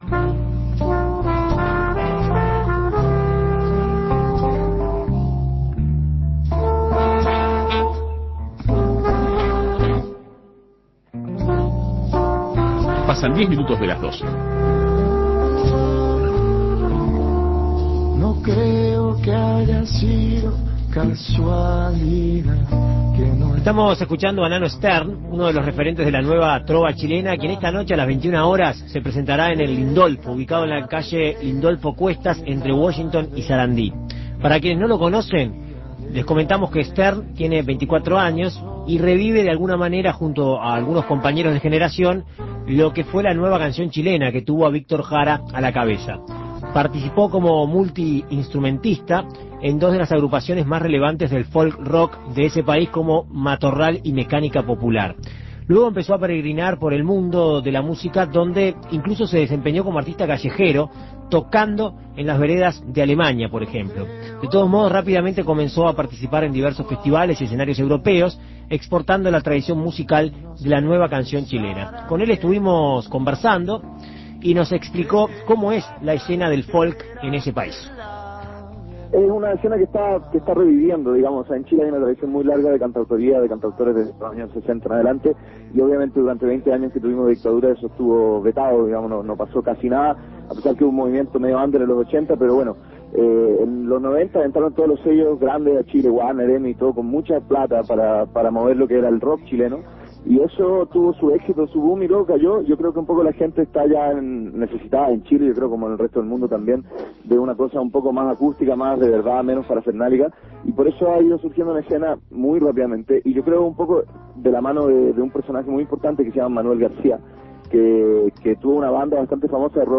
En Perspectiva Segunda Mañana dialogó con el artista sobre su visita al país y los comienzos de su carrera.